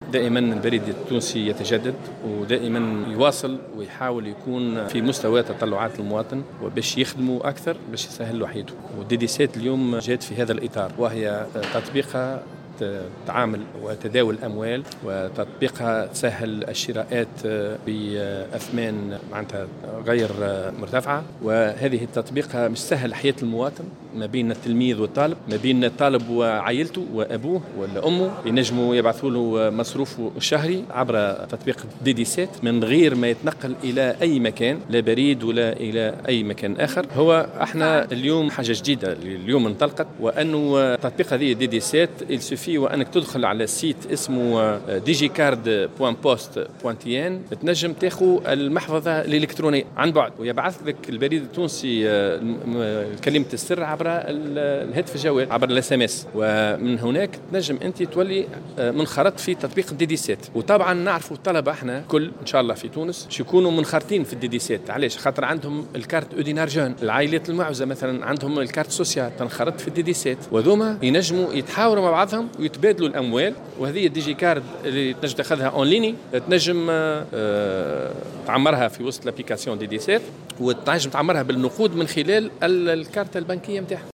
خلال لقاء إعلامي نظمه البريد التونسي بالمناسبة